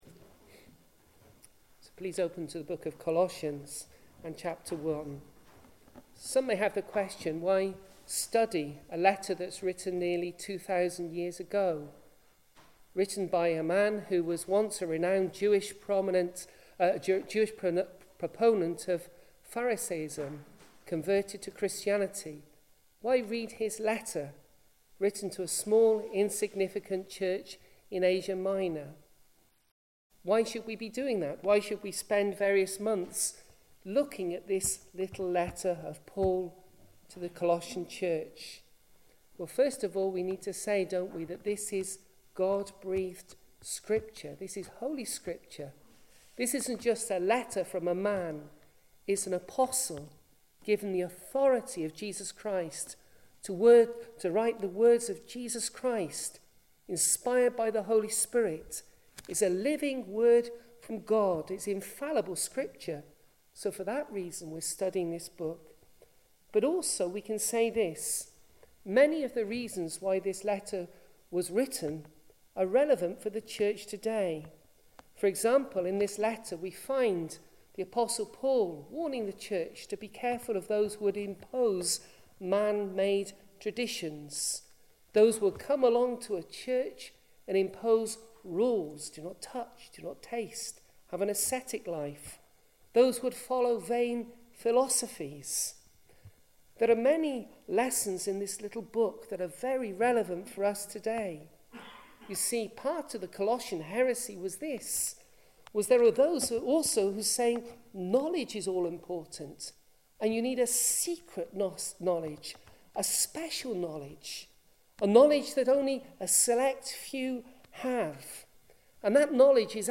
Colossians 1:1-12 Service Type: Sunday Evening Introduction Why study a book written more than a thousand years ago?